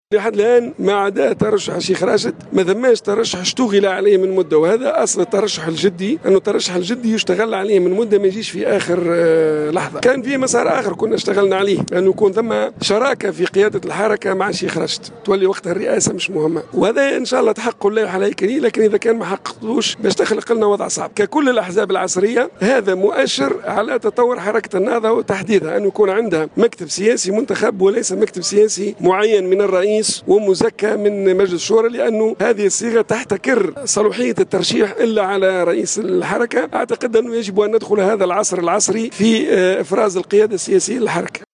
على هامش حضوره في المؤتمر العاشر للحركة المنعقد بالحمامات